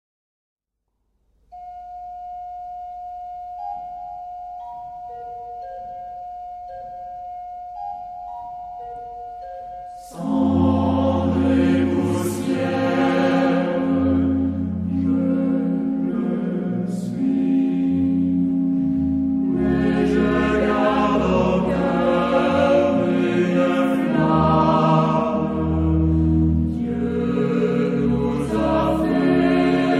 Genre-Stil-Form: geistlich ; Hymnus (geistlich)
Charakter des Stückes: langsam
Chorgattung: SAH  (3-stimmiger gemischter Chor )
Instrumente: Orgel (1)
Tonart(en): fis-moll